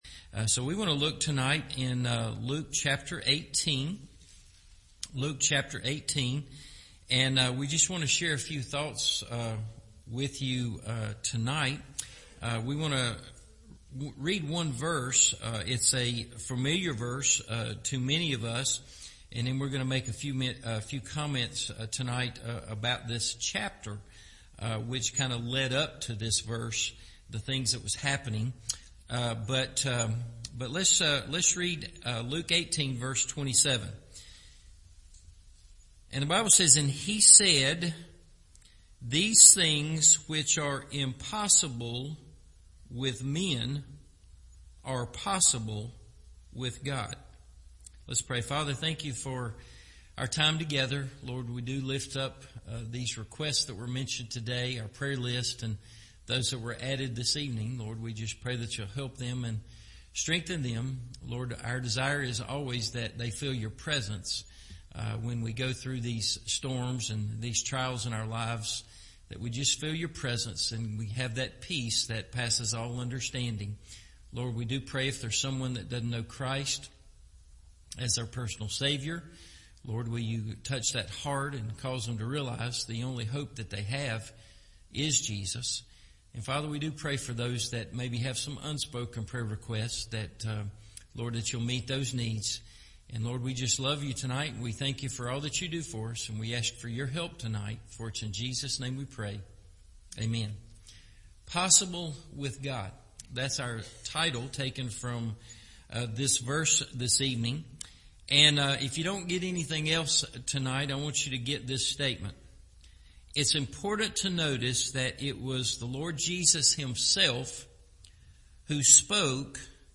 Possible With God – Evening Service